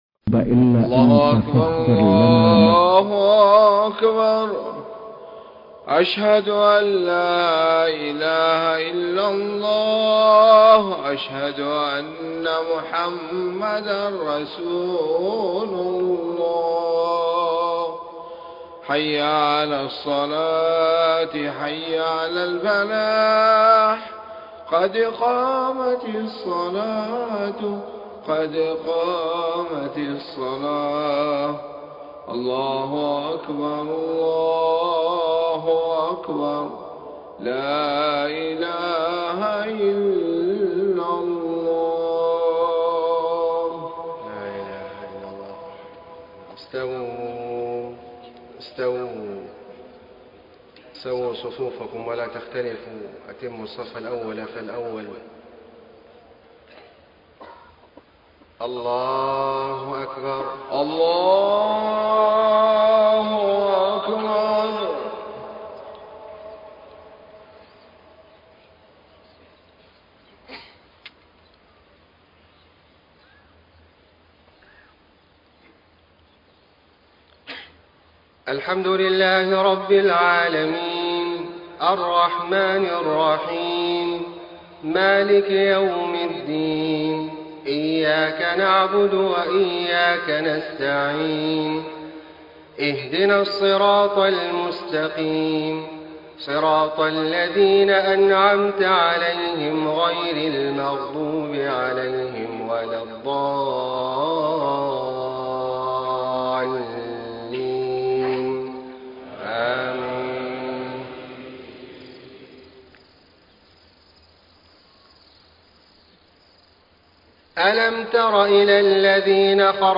صلاة الفجر 6 - 4 - 1434هـ من سورة البقرة 243-252 > 1434 🕋 > الفروض - تلاوات الحرمين